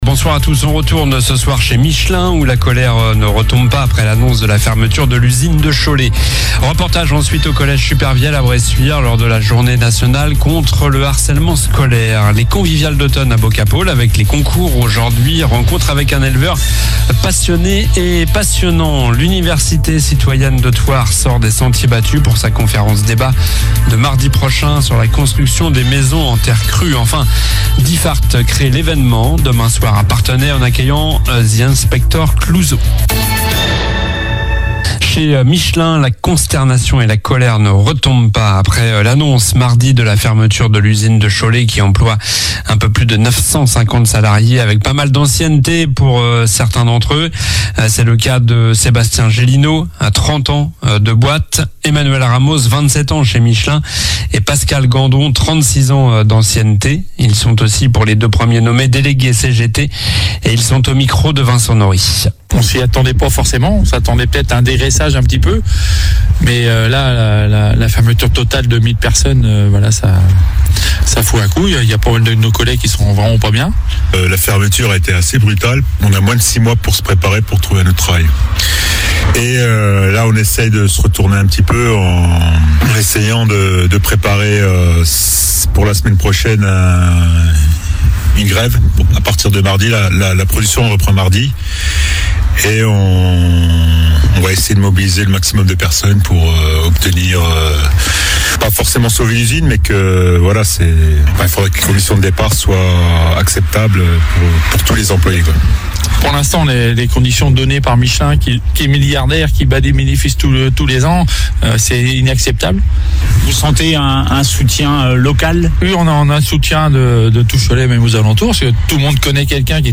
Journal du jeudi 7 novembre (soir)